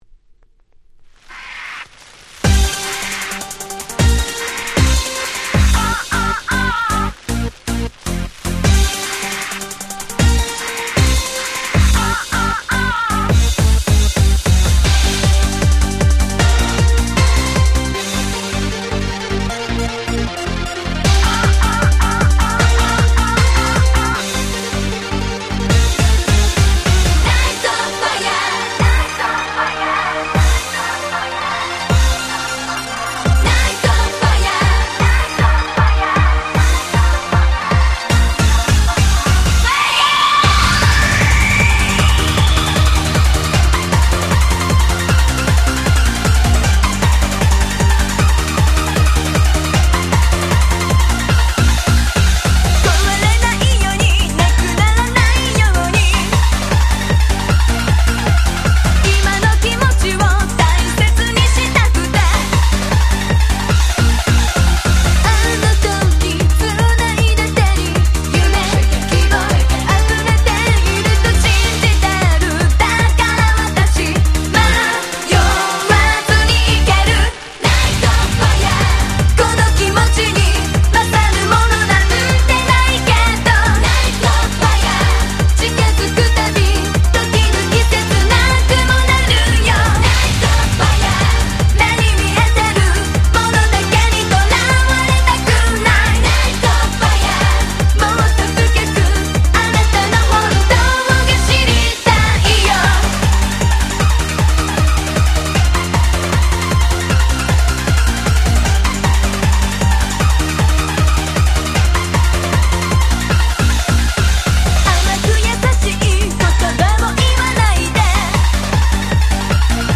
01' Very Nice J-Pop / Super Euro Beat !!